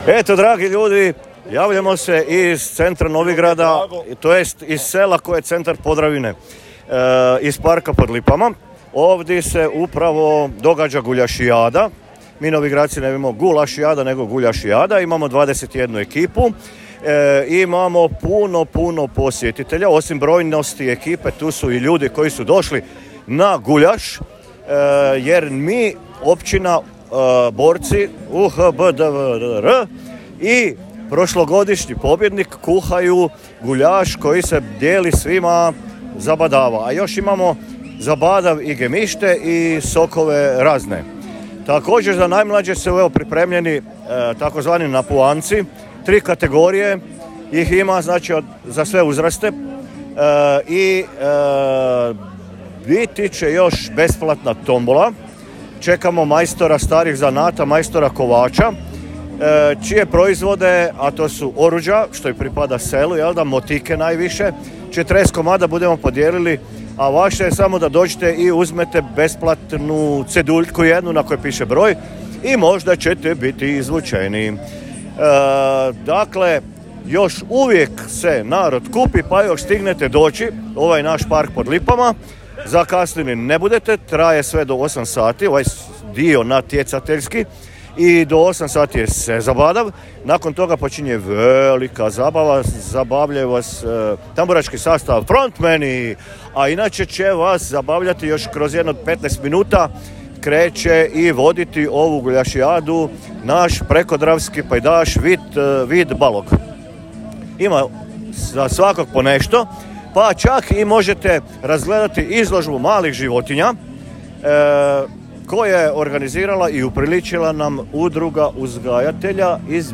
Načelnik općine Novigrad Podravski Zdravko Brljek s oduševljenjem za Podravski radio;